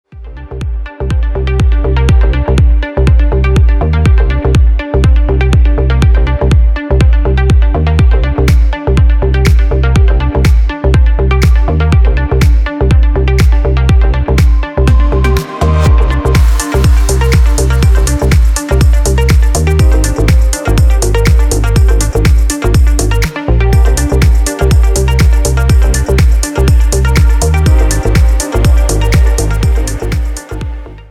• Качество: 320, Stereo
dance
EDM
спокойные
без слов
house